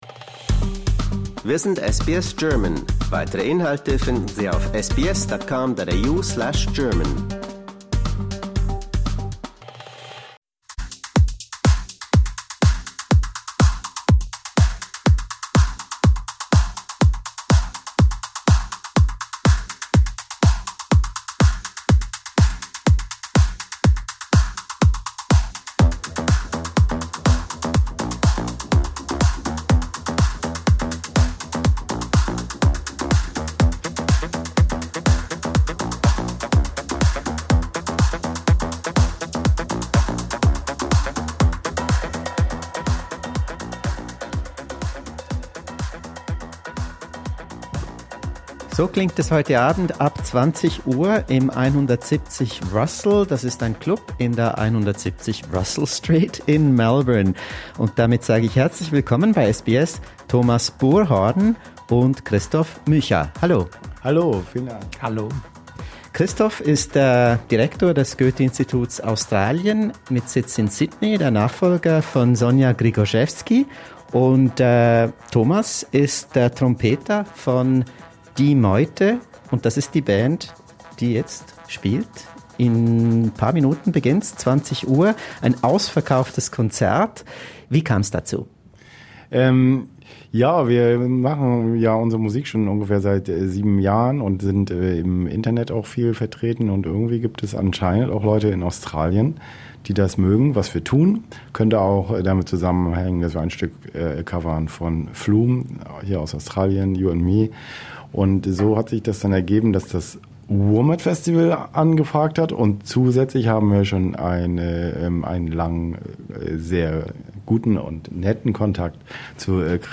Listen to a revealing interview from Australia to find out more.